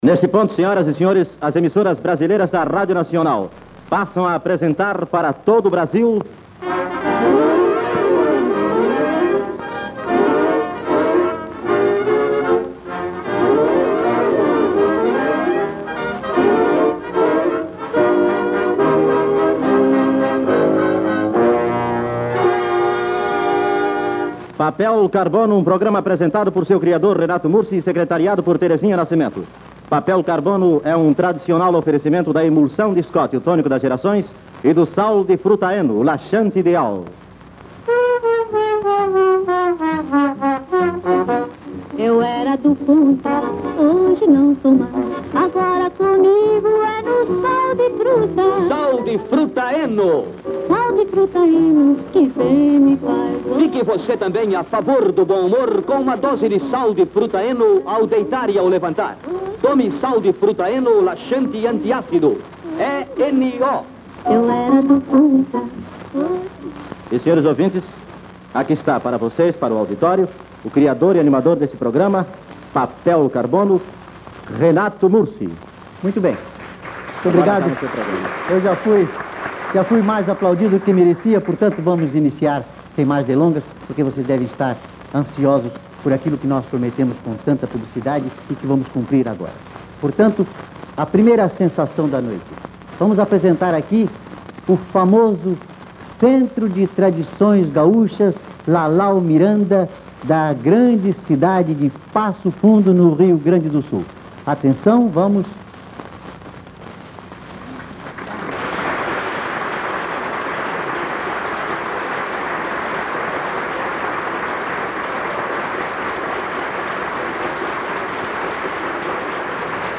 Programa Papel Carbono transmitido pela Radio Nacional do Rio de Janeiro